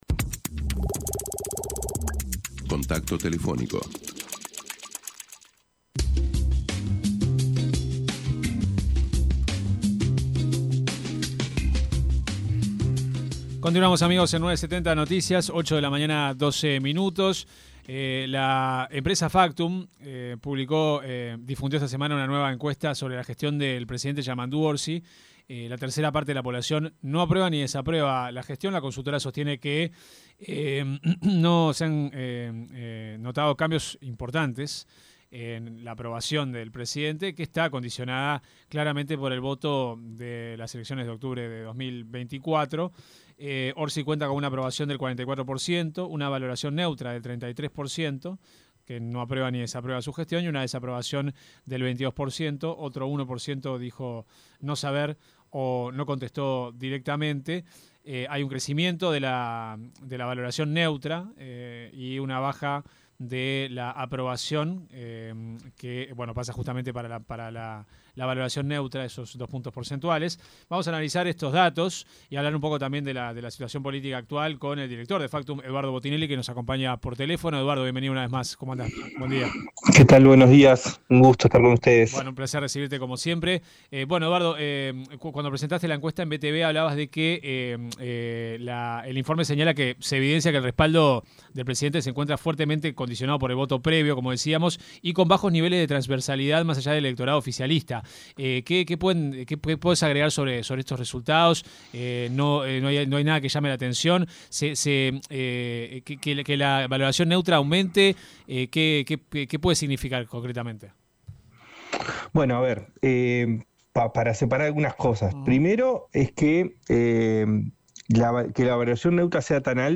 se refirió en una entrevista con 970 Noticias